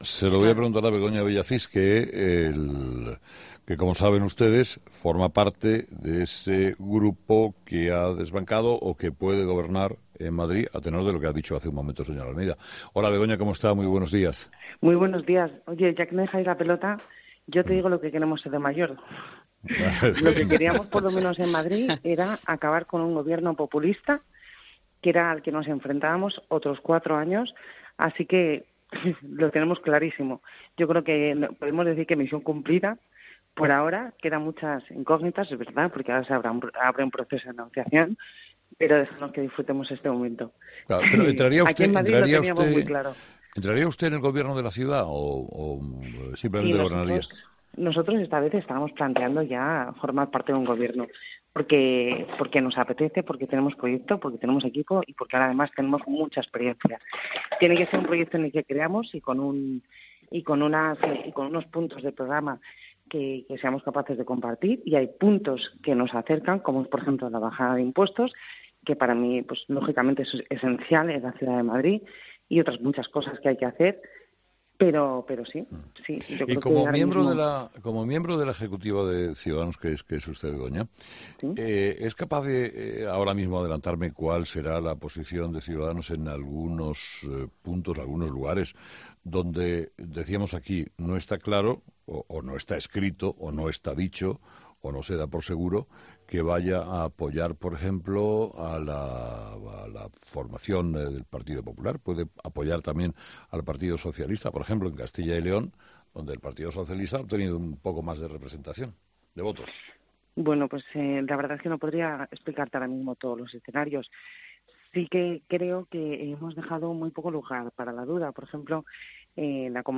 Entrevista: Begoña Villacís
Entrevistado: "Begoña Villacís"